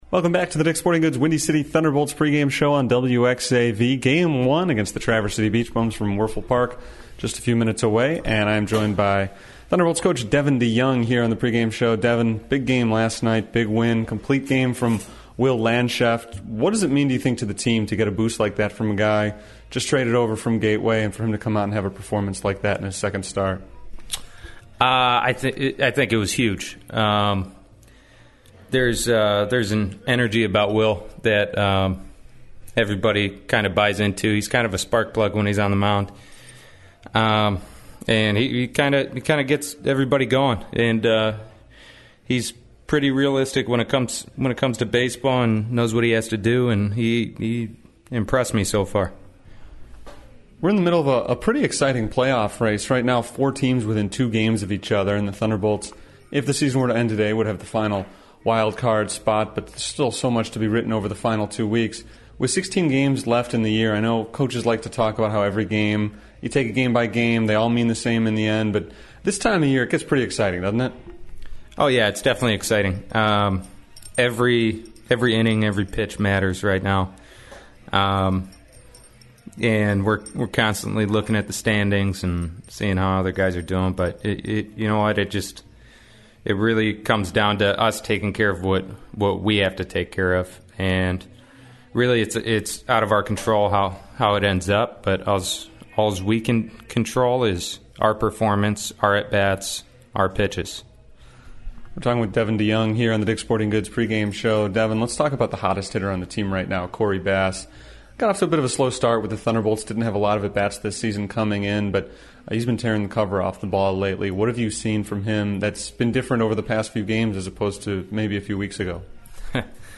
And another interview that aired pregame on August 18, 2017: